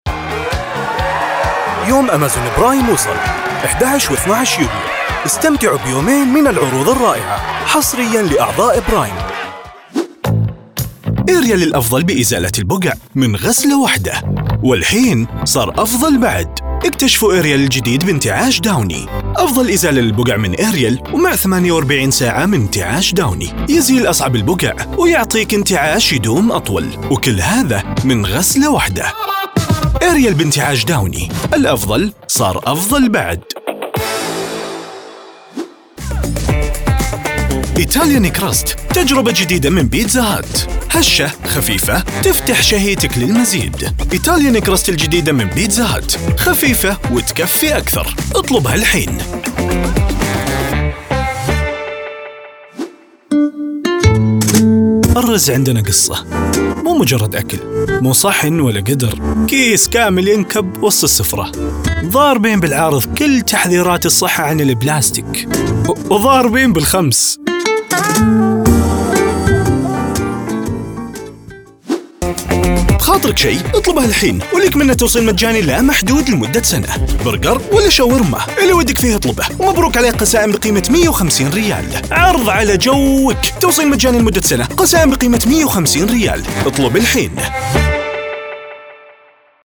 Arabische voice-over
Commercieel, Diep, Natuurlijk, Vertrouwd, Zakelijk
Commercieel